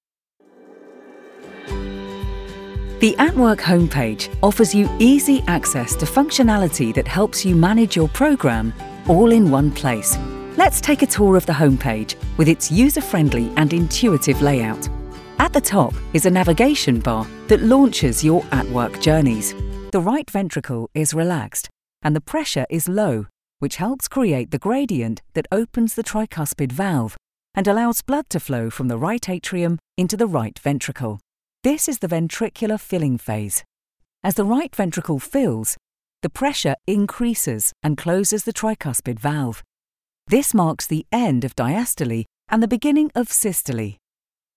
English (British)
Explainer Videos